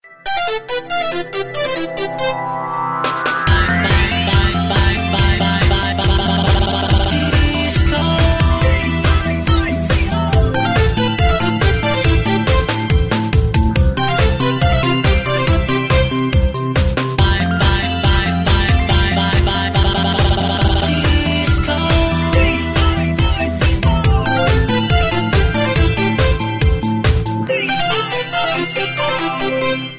В стиле Диско